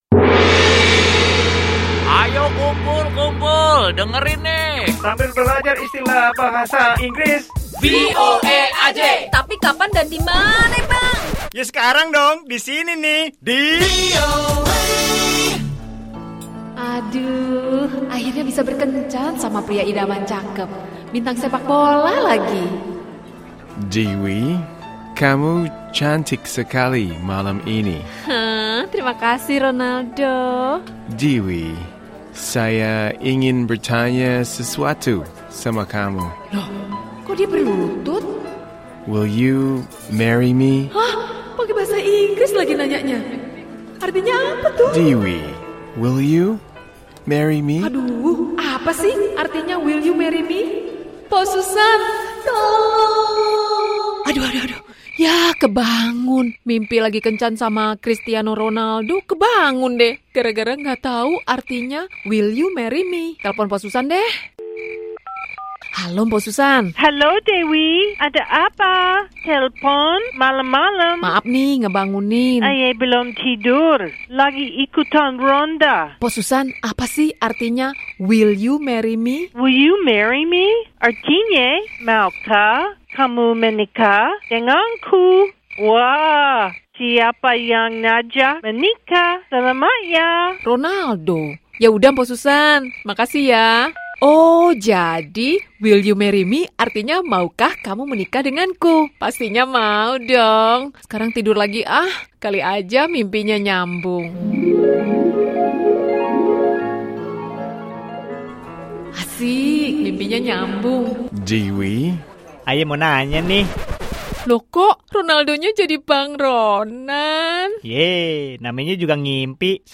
Kali ini ada percakapan tentang cara pengucapan dan penggunaan kata "Will you marry me" yang artinya maukah kamu menikah dengan saya ?